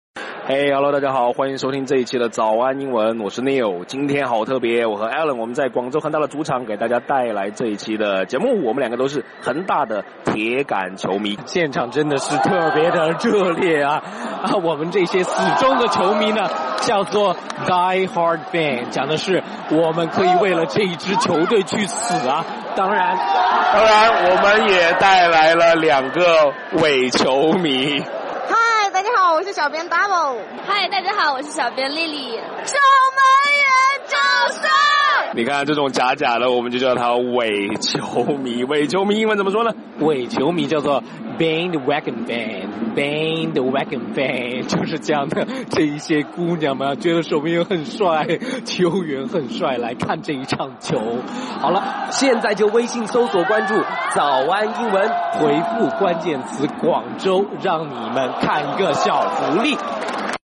早安英文 第93期:在恒大现场跟你说铁杆球迷的英文!